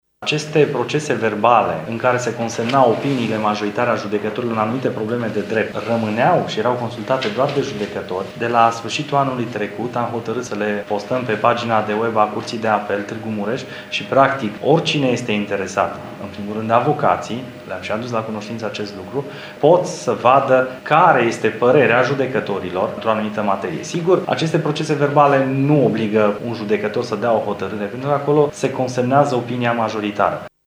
Preşedintele Curţii de Apel Tîrgu-Mureş, judecătorul Florin Dima, a arătat că la nivelul instanţelor mureşene s-au făcut în 2014 patru astfel de întâlniri şi că judecătorii pot consulta on-line opiniile majoritare cu privire la diverse cauze deduse judecăţii: